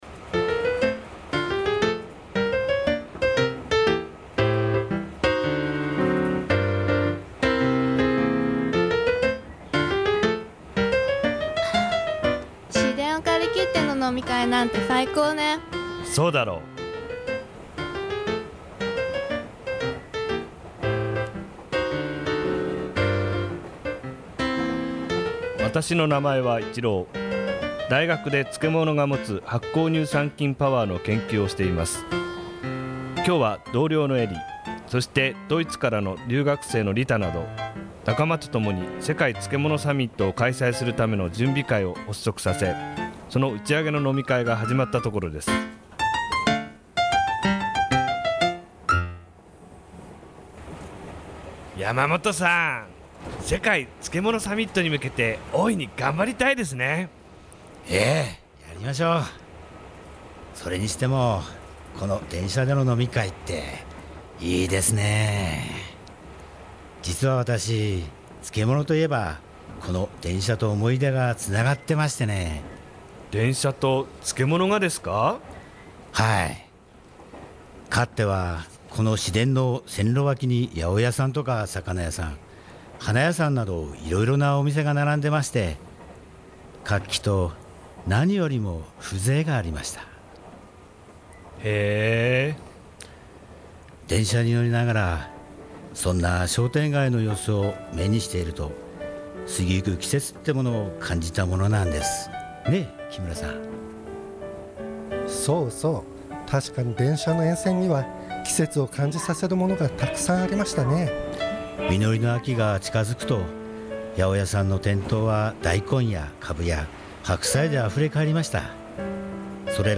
ラジオドラマ「目利き人の行方－やさい編－」がＦＭアップルで放送